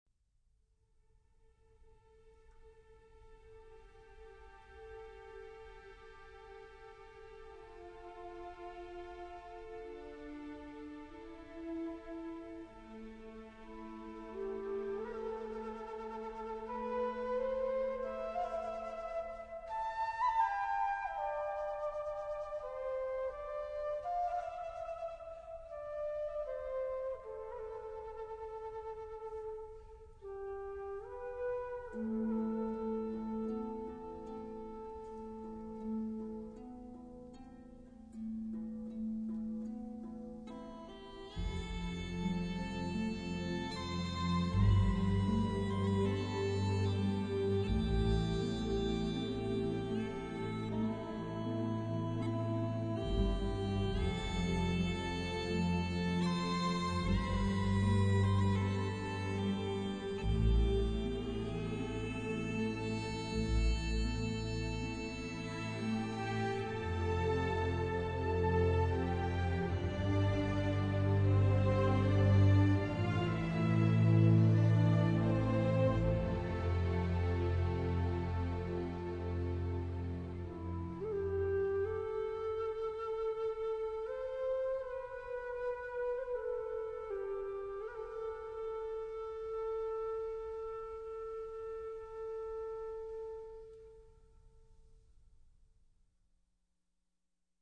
MUCH better quality now !